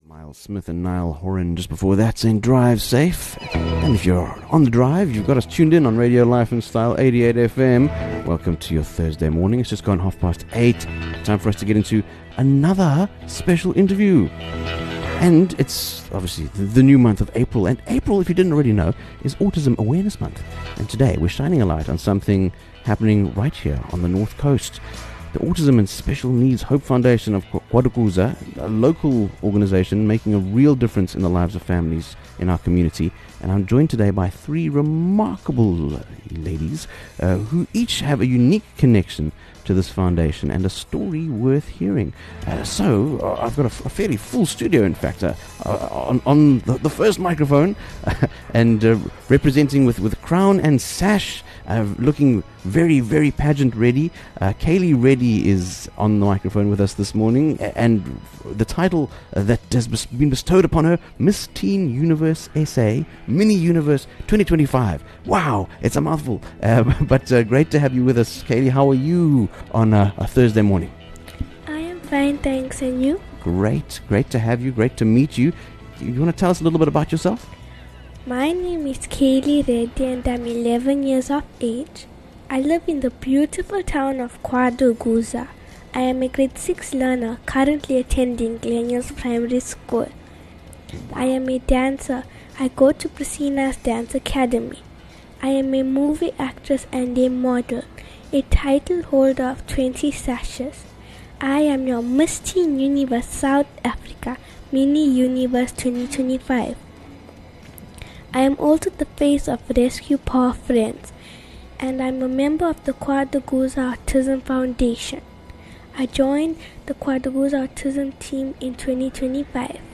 Join us for an inspiring conversation with the Autism & Special Needs Hope Foundation